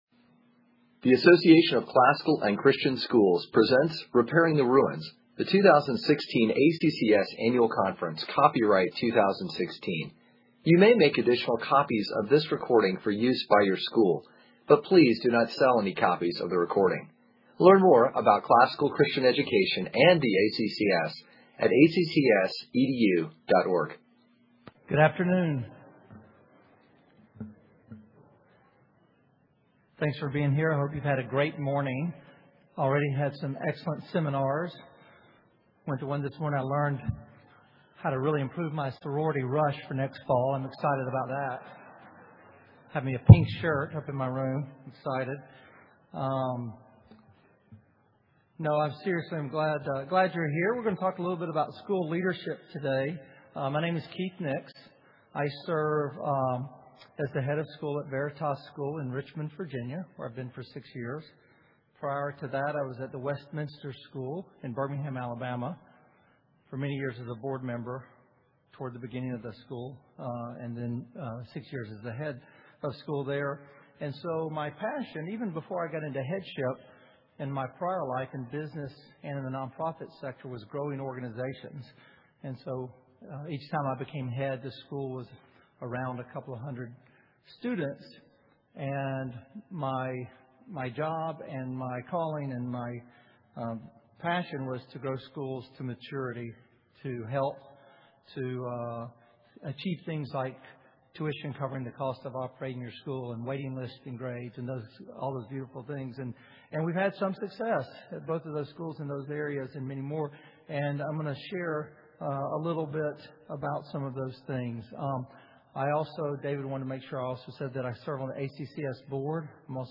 2016 Leaders Day Talk | 51:01:00 | Fundraising & Development, Leadership & Strategic